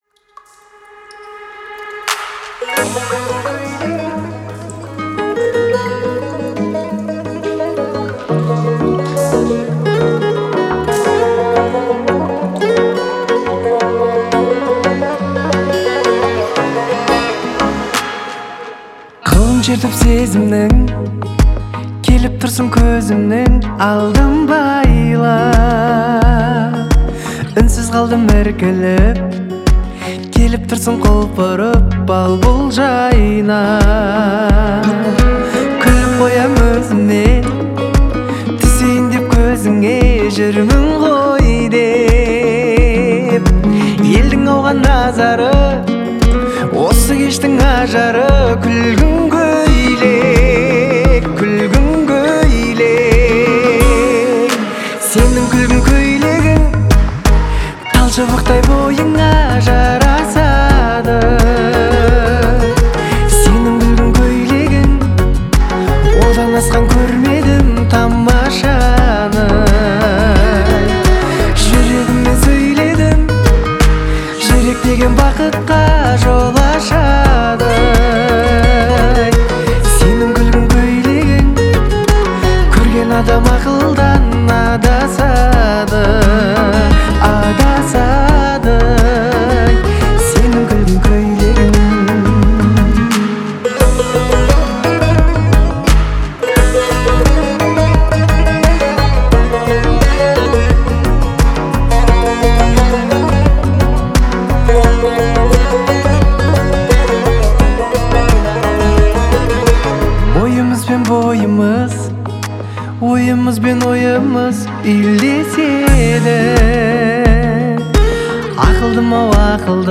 это романтическая песня в жанре поп-музыки